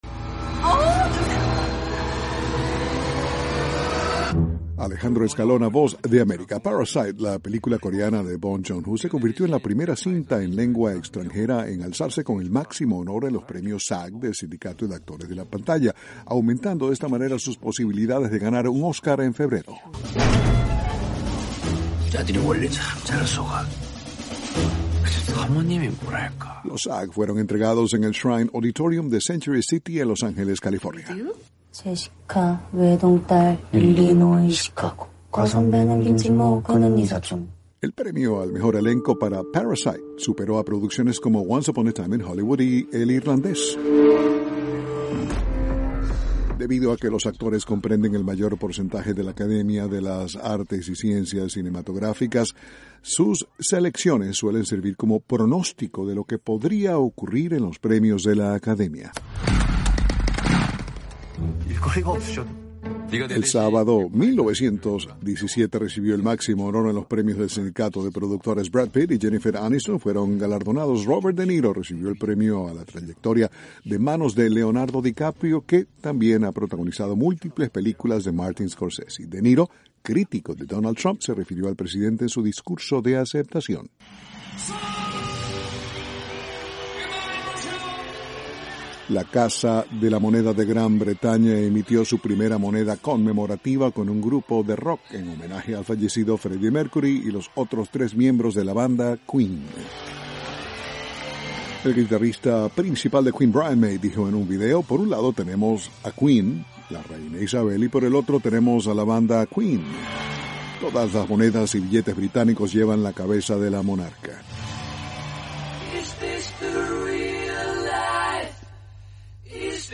informa desde Washington...